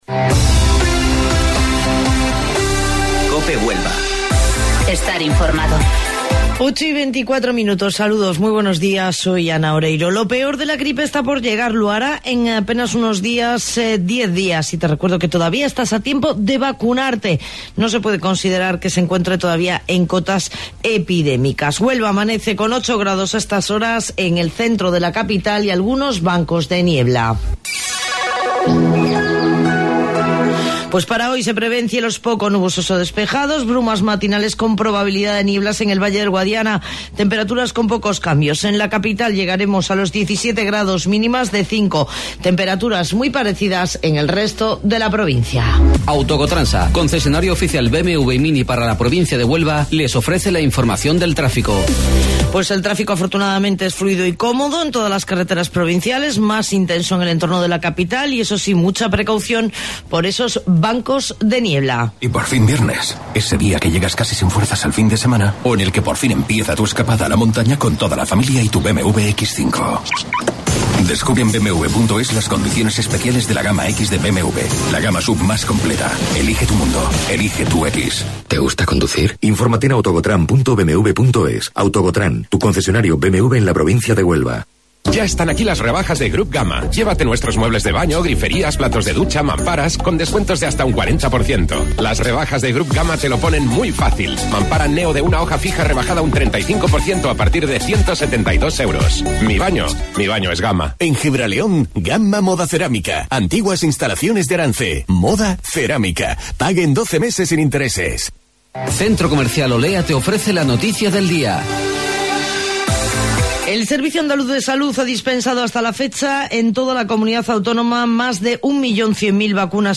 AUDIO: Informativo Local 08:25 del 10 de Enero